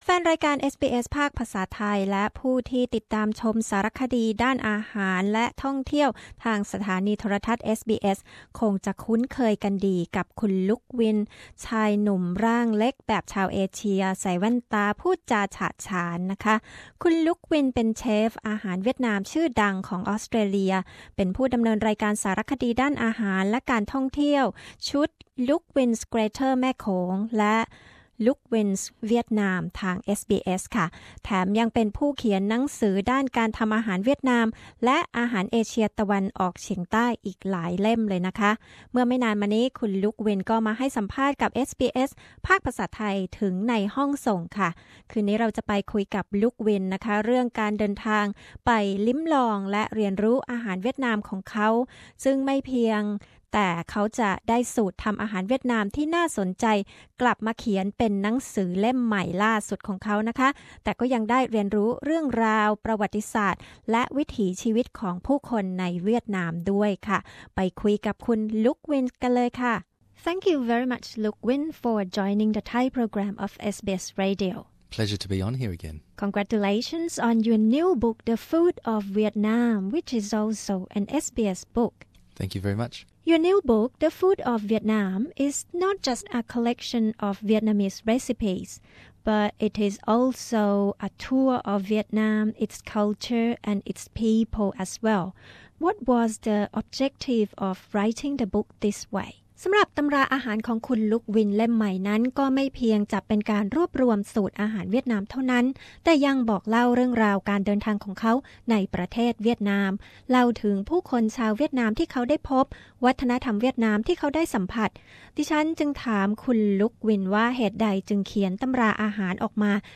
The first episode of an exclusive Interview with Luke Nguyen, the acclaimed Vietnamese restauranteur and host of SBSs popular South East Asian food travel show. He explains about distinctive characteristics of Vietnamese cuisine and tells us about his culinary journey to sample and learn about aged-old recipes from North to South of Vietnam.